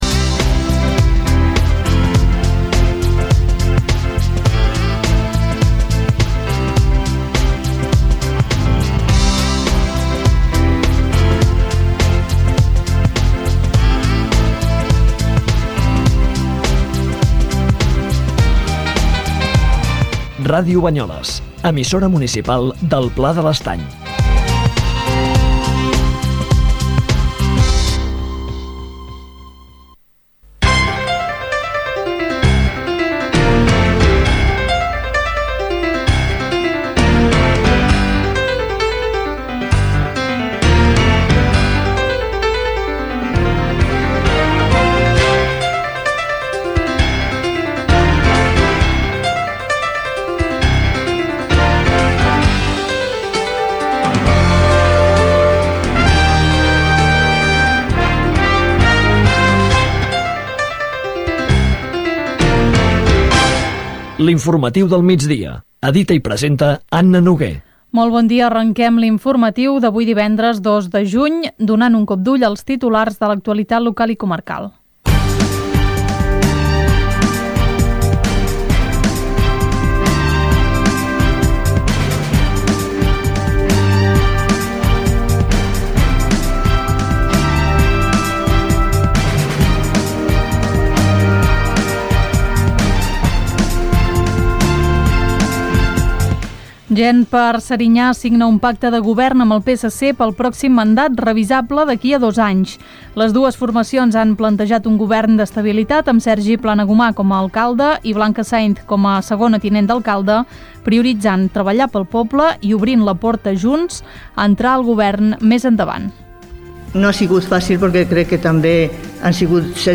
Indicatiu de l'emissora, careta, data, titulars locals i comarcals, estat del temps, indicatiu del programa.
Informatiu